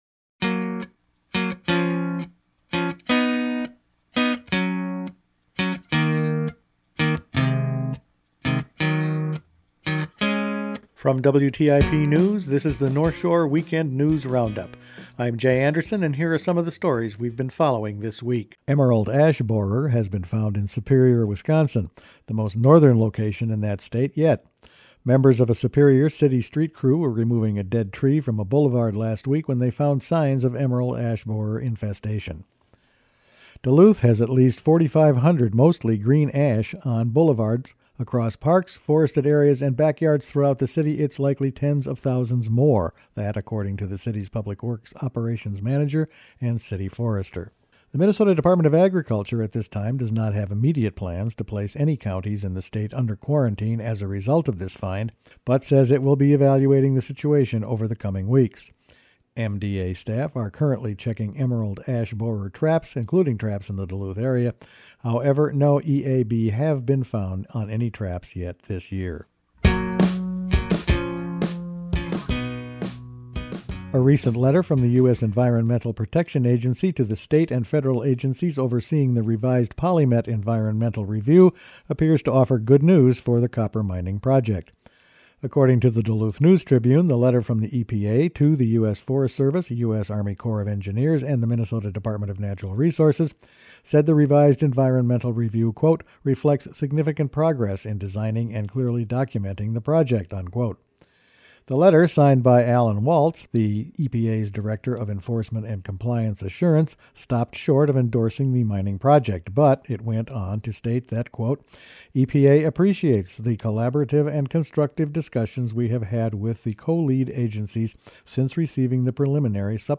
Weekend News Roundup for August 17
Each week the WTIP news staff puts together a roundup of the news over the past five days. Emerald ash borers have been found in Superior, Wisconsin. An MPA review of preliminary PolyMet environmental documents is questioned.